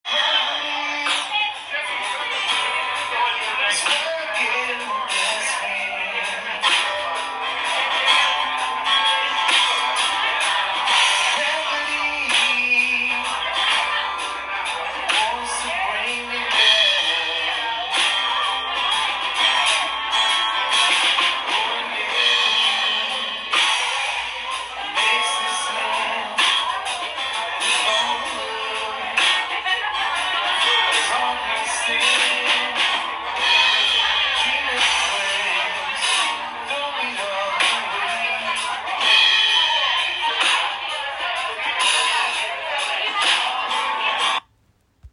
Szukam nazwy oryginalnej piosenki, którą wykonywał zespół w jednym z pubów.